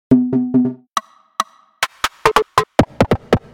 How to Create Percussions in Sylenth1 (Plus Download)